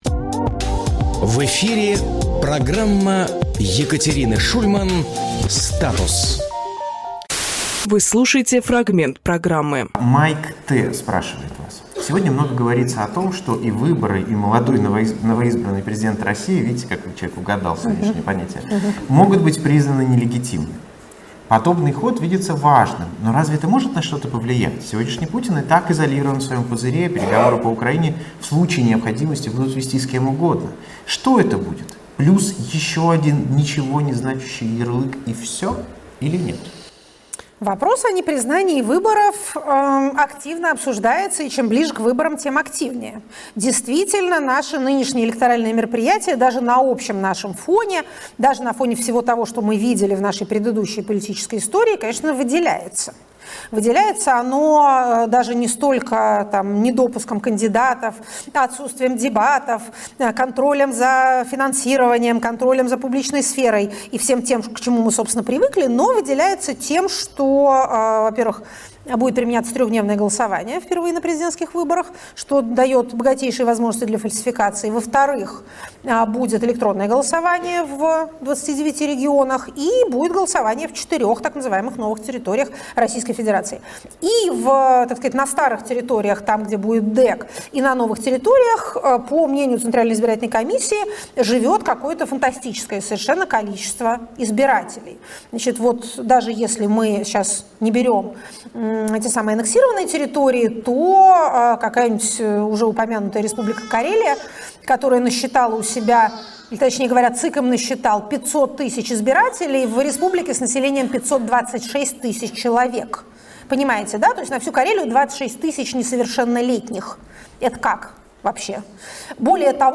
Екатерина Шульманполитолог
Фрагмент эфира от 27.02.24